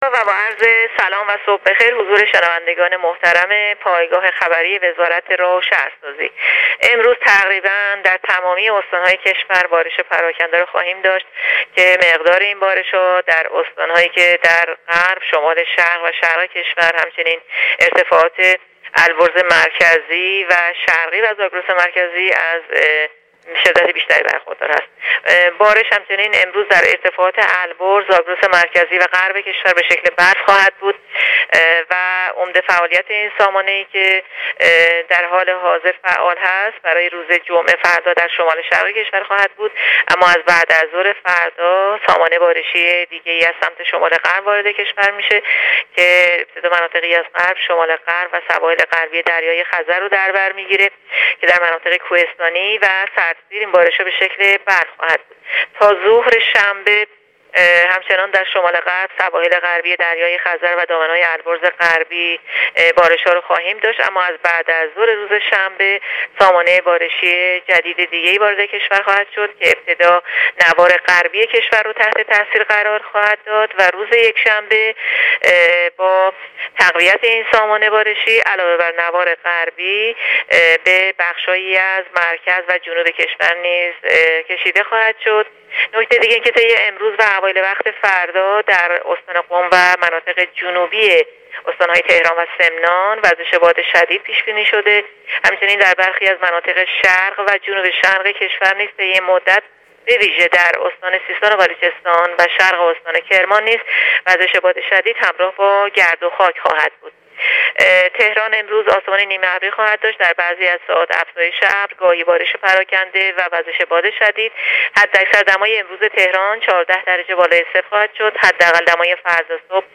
در گفت‌و‌گو با راديو اينترنتی پايگاه خبری وزارت راه و شهرسازی